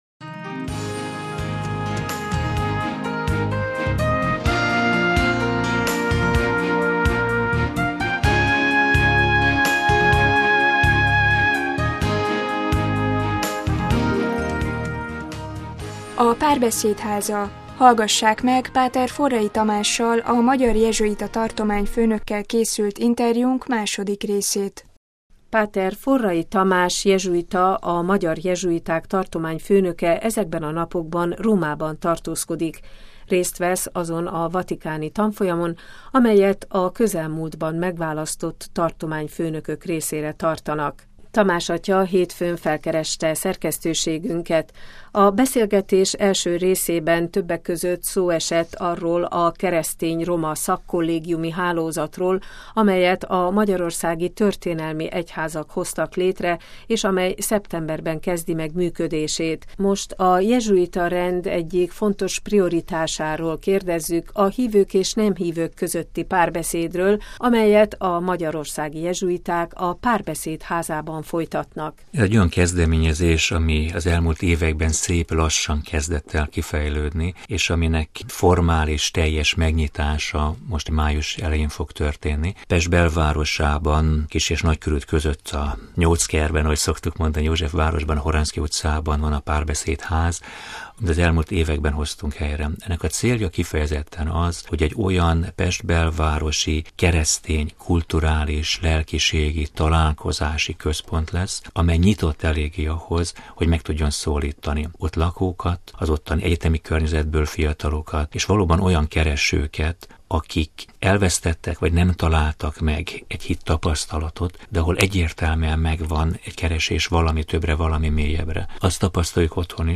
„A Párbeszéd Háza” - interjú